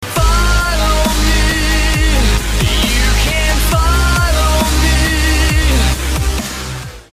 Follow Me Alert Sound Buttons